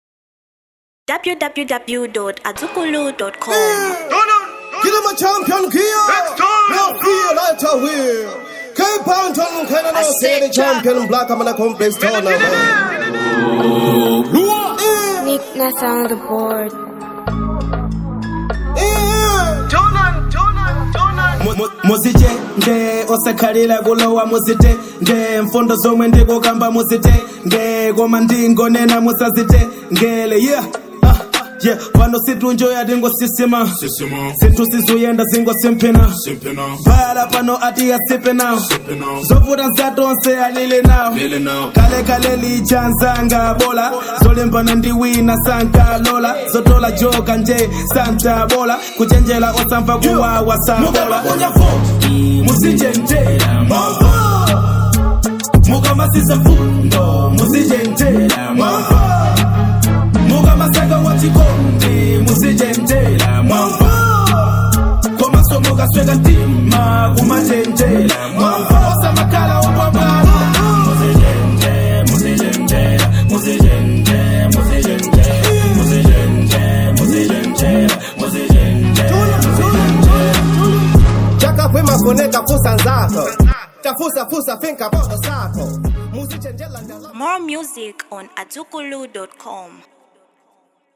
Genre HIP HOP